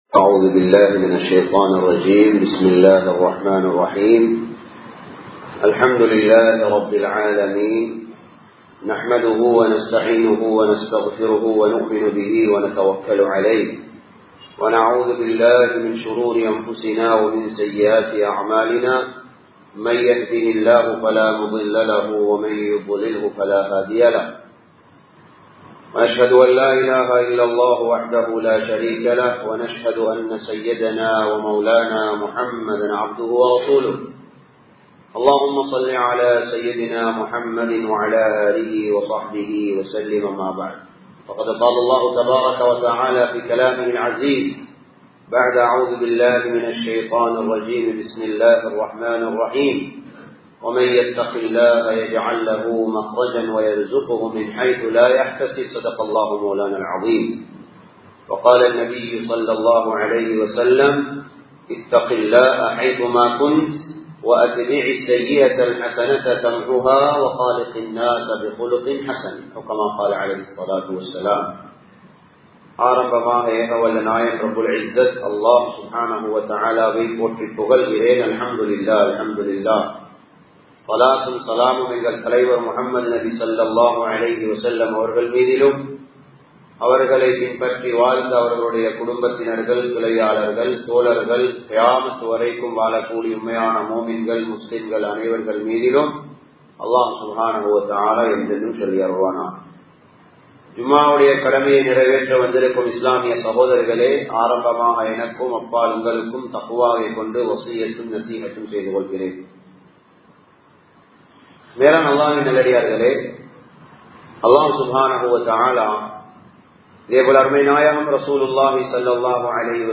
துஆக்களின் பெறுமதி | Audio Bayans | All Ceylon Muslim Youth Community | Addalaichenai
Colombo 08, Borella Jumua Masjith 2024-12-27 Tamil Download